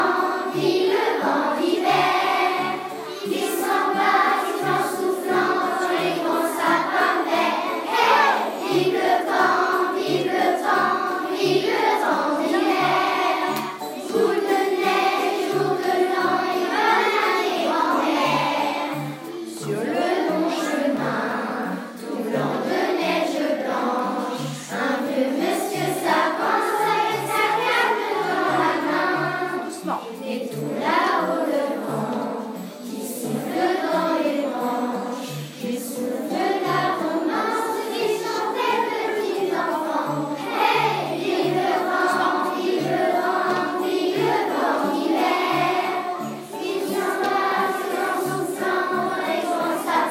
Ecole Elémentaire Publique de Prunay En Yvelines Chorale
par les élèves